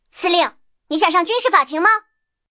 M26潘兴司令部语音3.OGG